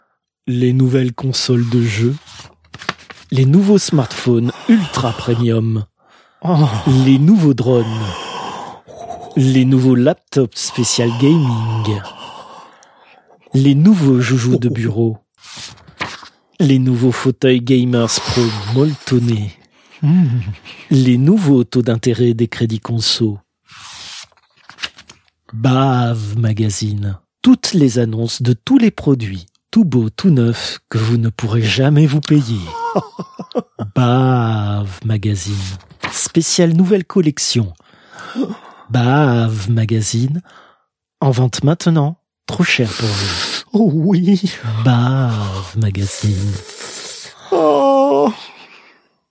Chroniques › Fausse publicité
Extrait de l'émission CPU release Ex0228 : lost + found (janvier 2026).